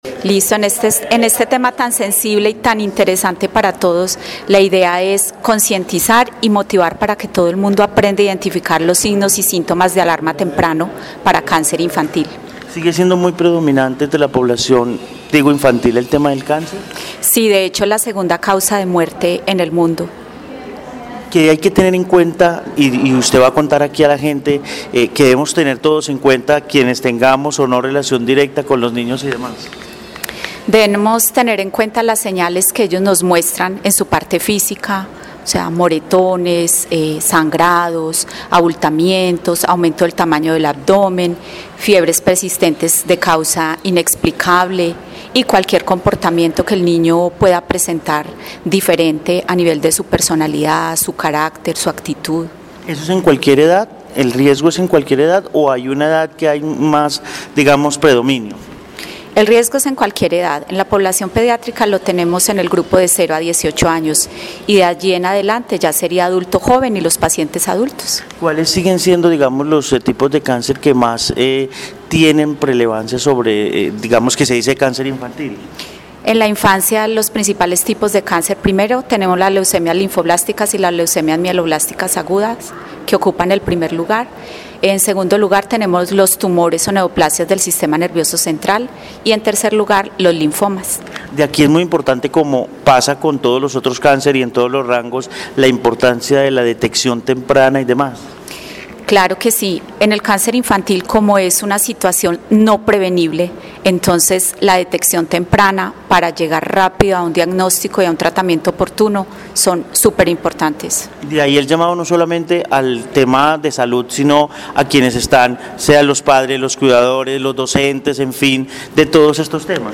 En el hospital San Juan de Dios de Armenia hubo conversatorio sobre el cáncer infantil